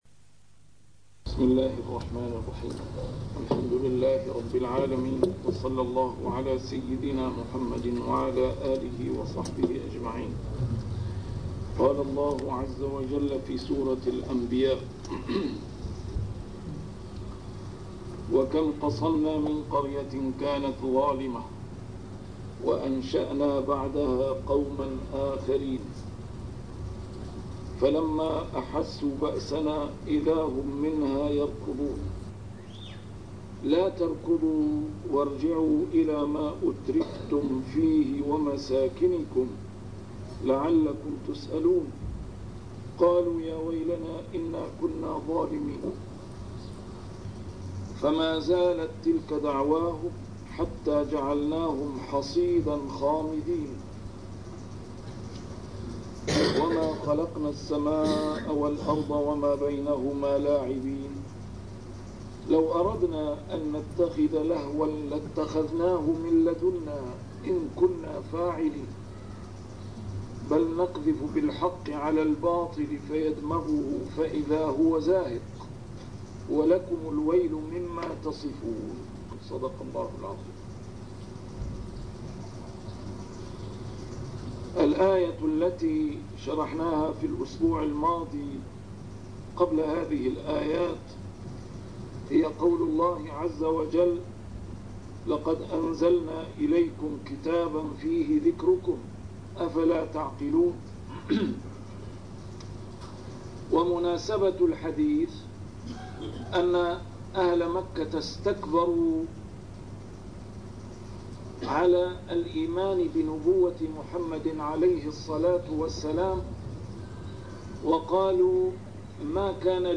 A MARTYR SCHOLAR: IMAM MUHAMMAD SAEED RAMADAN AL-BOUTI - الدروس العلمية - تفسير القرآن الكريم - تسجيل قديم - الدرس 84: الأنبياء 011-015